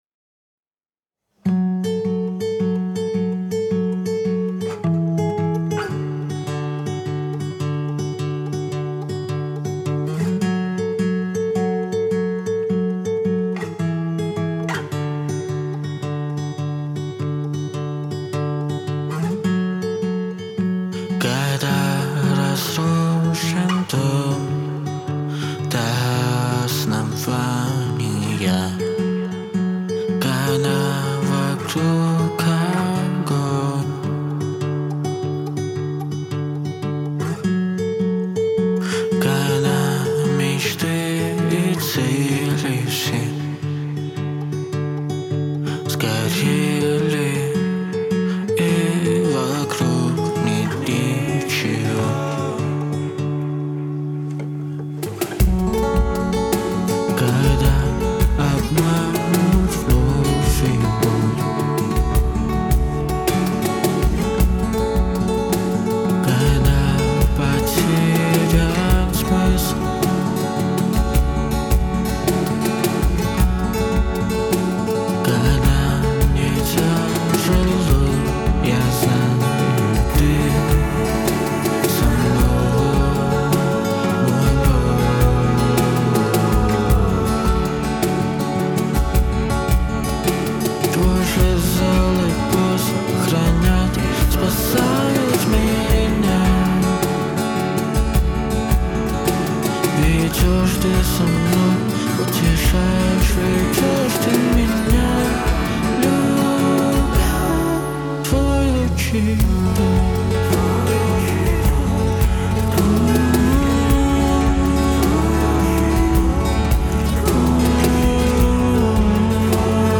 85 просмотров 139 прослушиваний 5 скачиваний BPM: 160